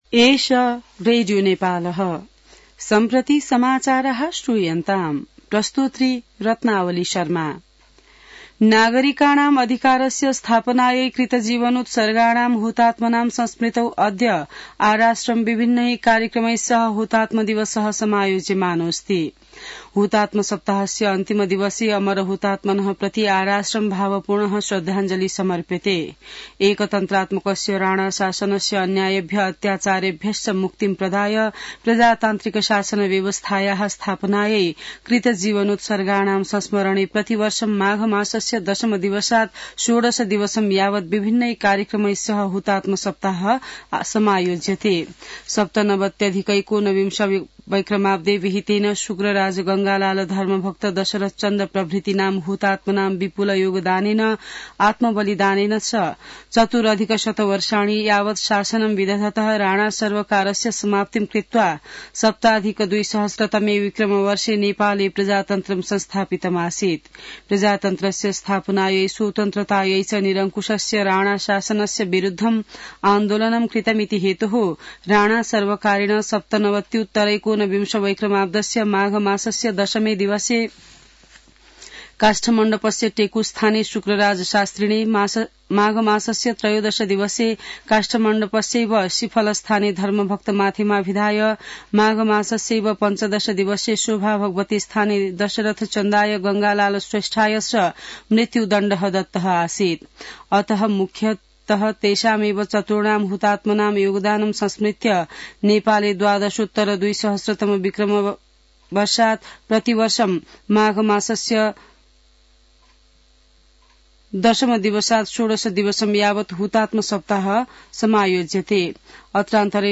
संस्कृत समाचार : १७ माघ , २०८१